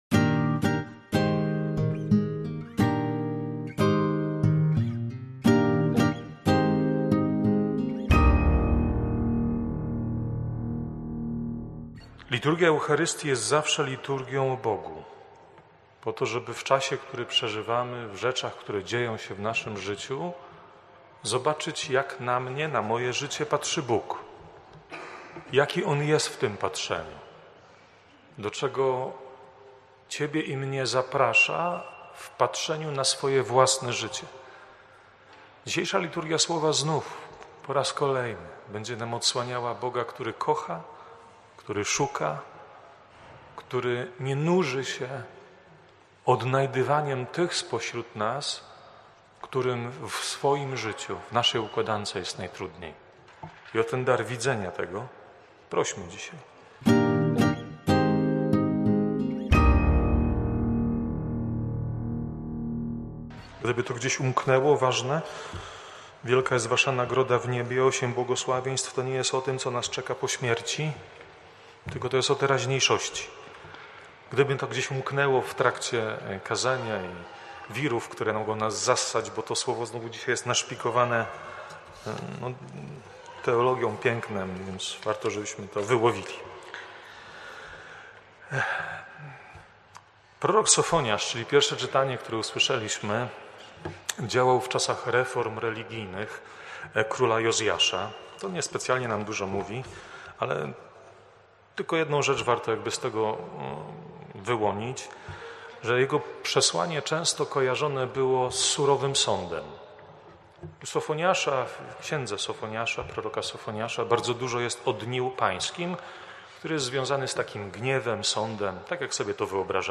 kazania.